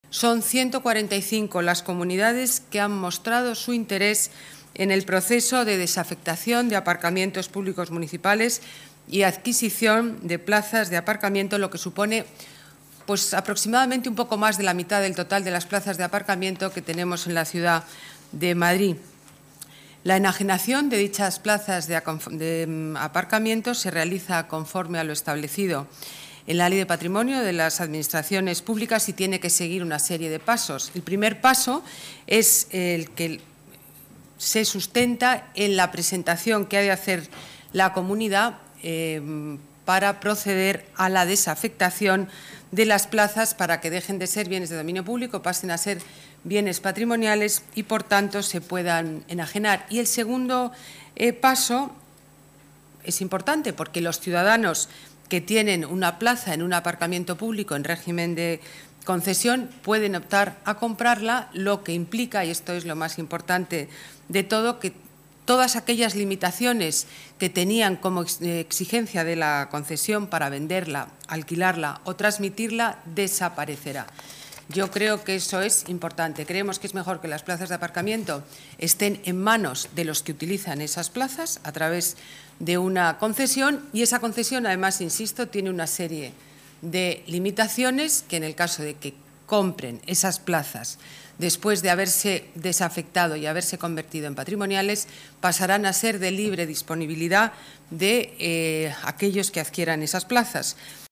Nueva ventana:Declaraciones Botella: enajenación aparcamientos públicos